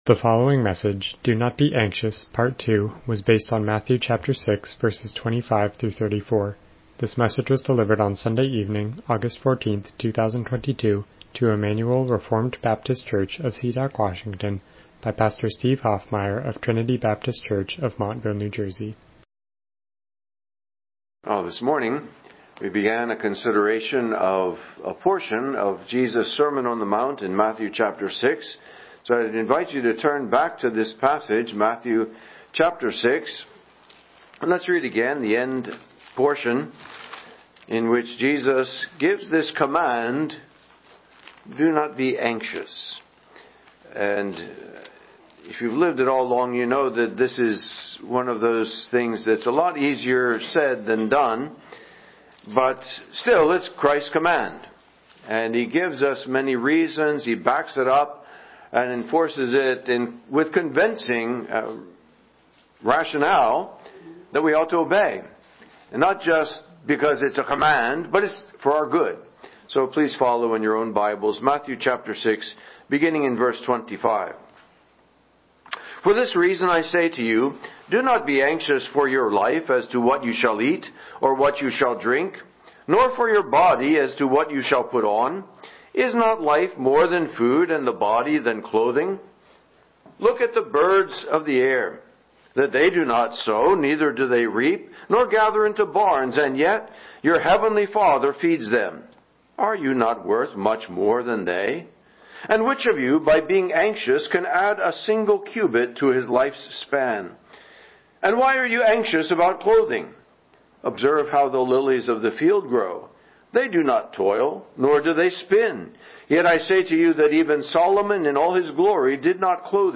Passage: Matthew 6:25-34 Service Type: Evening Worship « Do Not Be Anxious